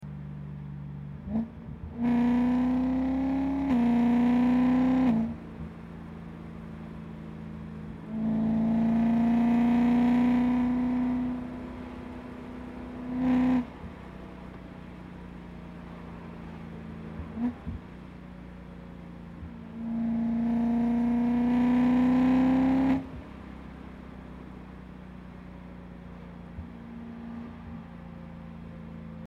E92 M3 Akrapovic Evolution Asmr